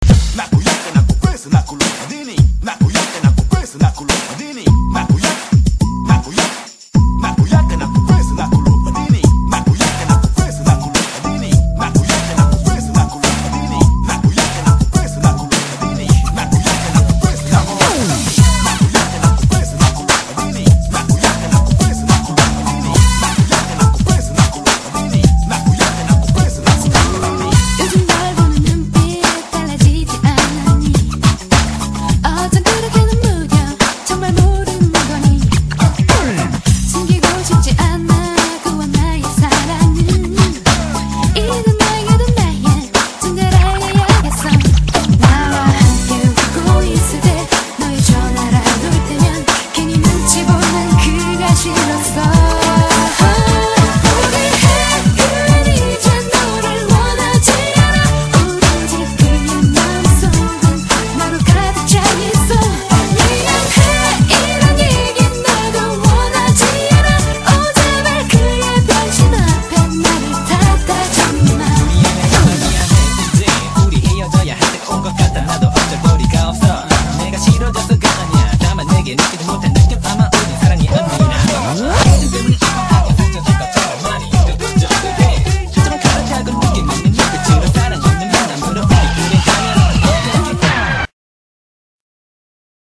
BPM105--1
Audio QualityPerfect (High Quality)